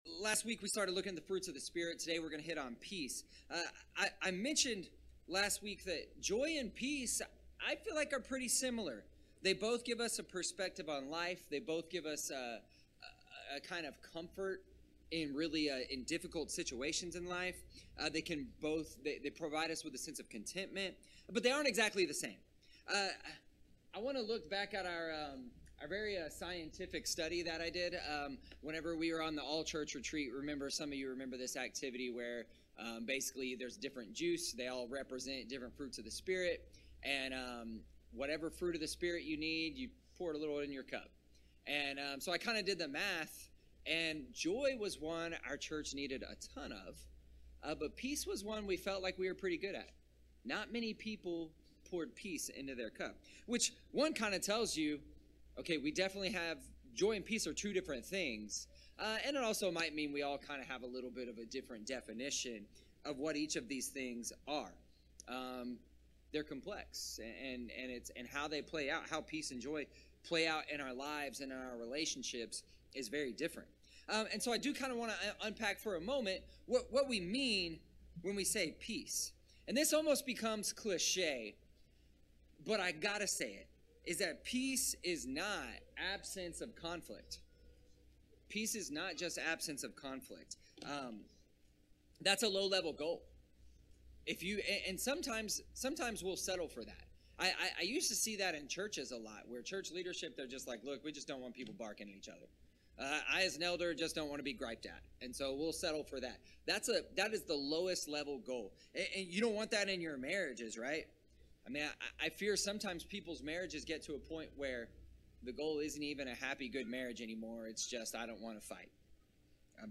This week's bulletin - 6/25/2023 More from the series: Fruits of the Spirit series ← Back to all sermons